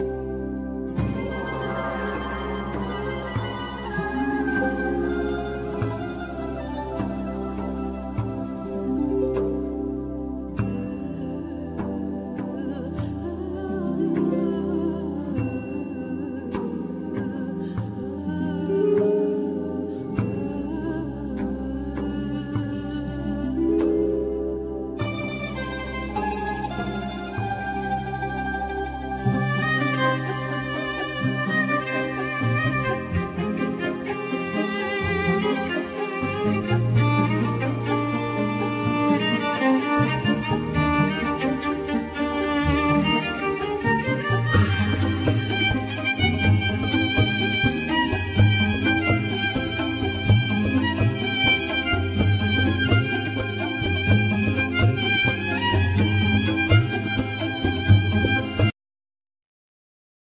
Cretan lyra
Duduk,Shevi
Nylon string guitar,Mandokino,Bass